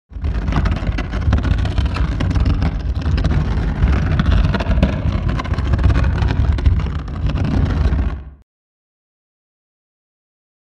Wood Scrape; Heavy Wooden Object Drags Across Rough Surface With Rumble.